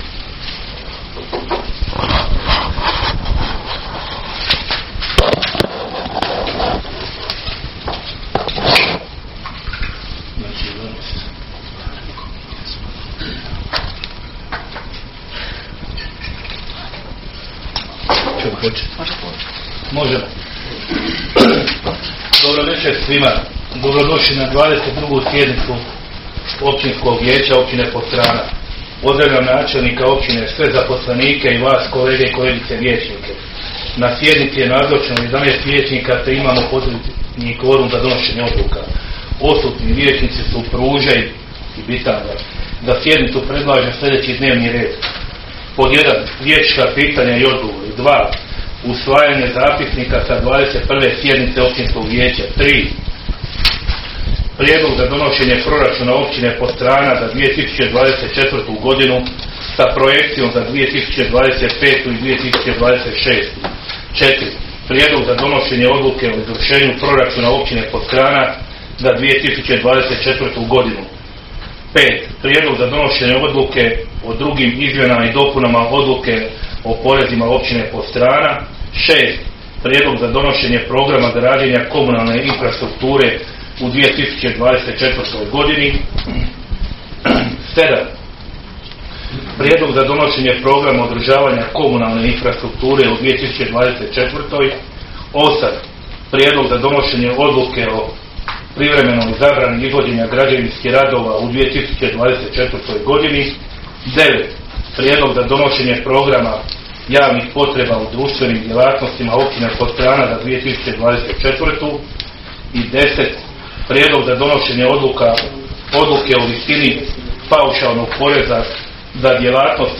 za dan 11. prosinca (ponedjeljak) 2023. godine u 19,00 sati u vijećnici Općine Podstrana,